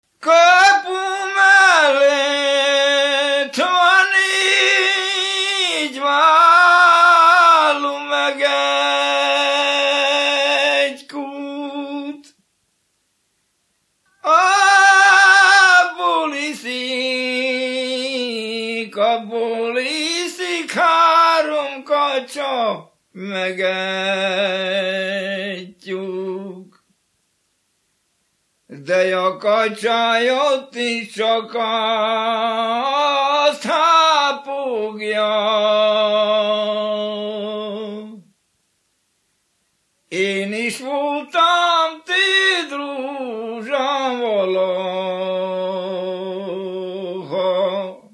Erdély - Szolnok-Doboka vm. - Szék
ének
Műfaj: Keserves
Dallamtípus: Dudanóta (11 szótagos) 1
Stílus: 6. Duda-kanász mulattató stílus
Kadencia: 5 (1) 2 1